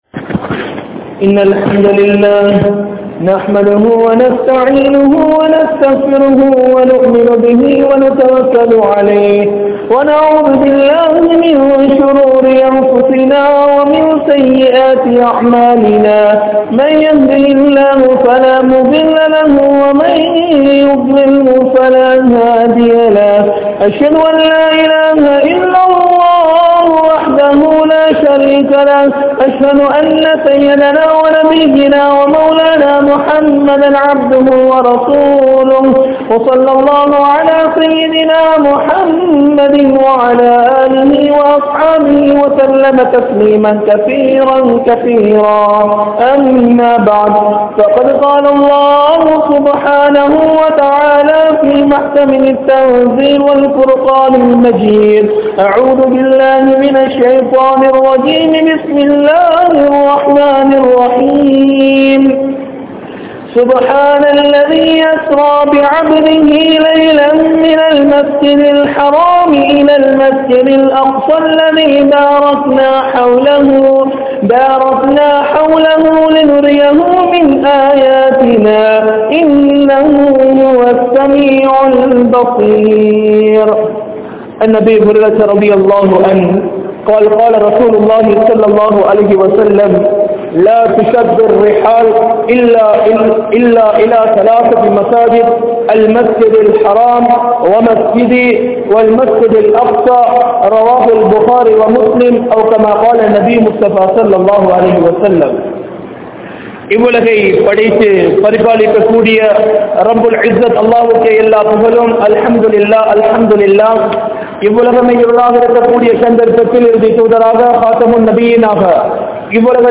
Masjidhul Aqsa`vin Varalaaru (மஸ்ஜிதுல் அக்ஸாவின் வரலாறு) | Audio Bayans | All Ceylon Muslim Youth Community | Addalaichenai
Kamachoda Jumua Masjith